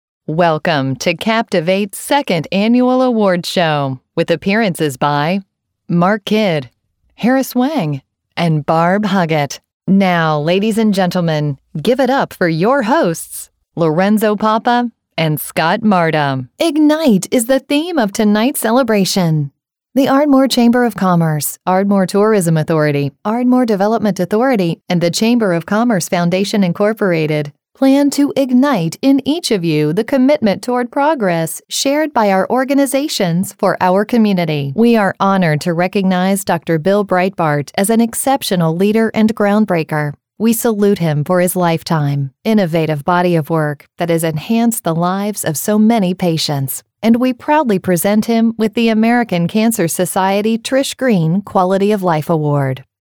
Live Announce - Female Voice of God (VOG)
Pre-recorded or live, in-person voiceover talent for your event.
Awards Ceremony